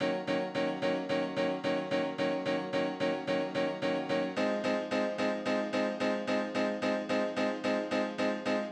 03 Piano PT1.wav